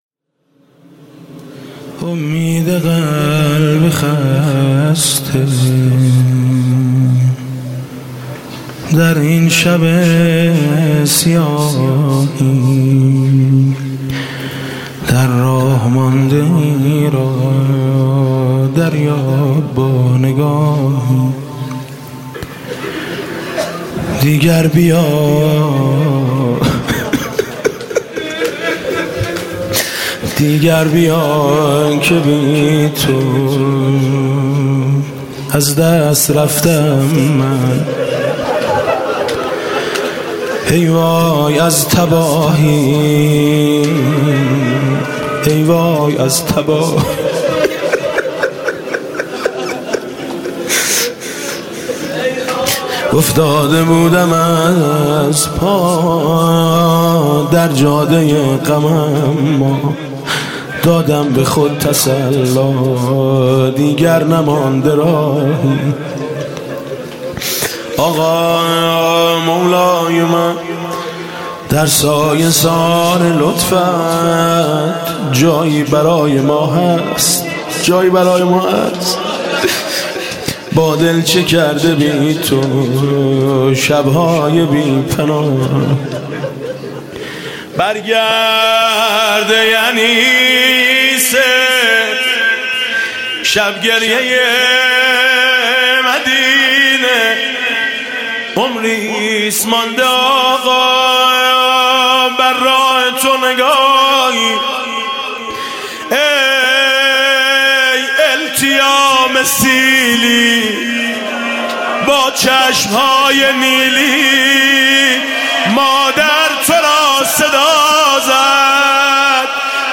شب اول محرم 96 - هیئت میثاق - مناجات با امام زمان (عج)
محرم 96